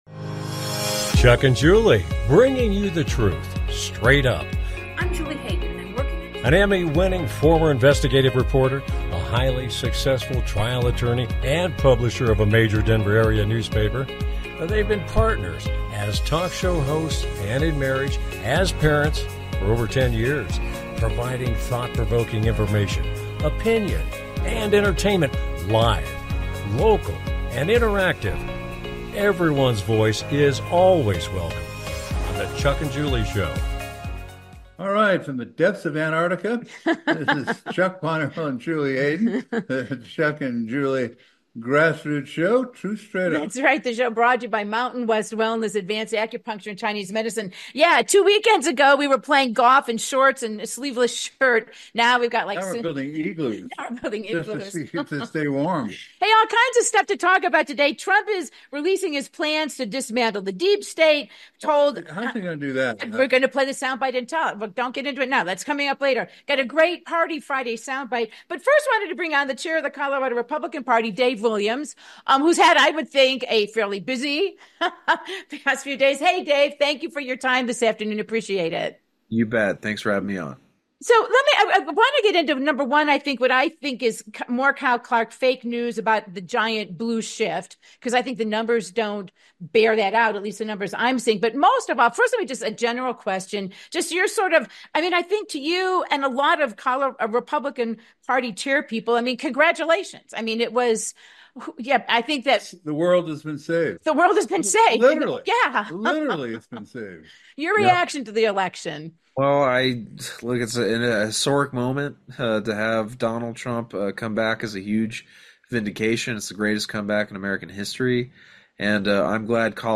With Guest, CO GOP Chair, Dave Williams - The 2024 election and where we go from here